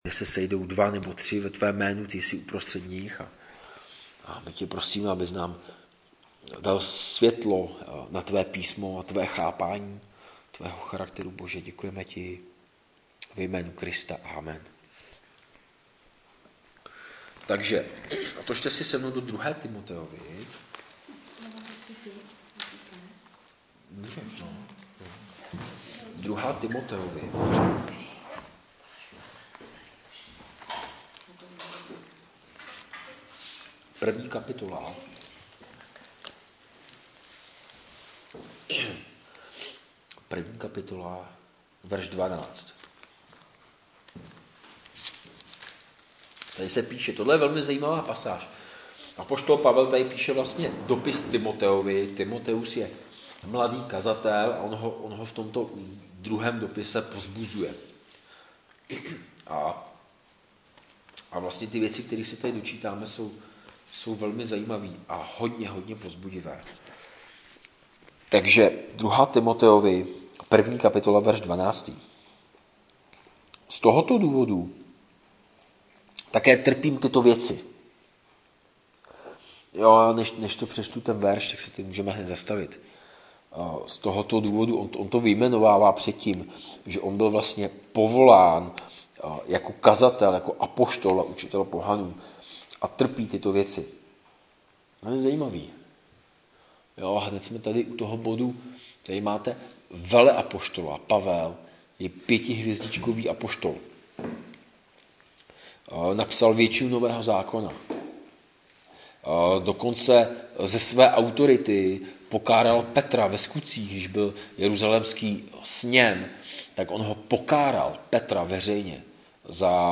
Ze série Jiné, kázání (Liberec)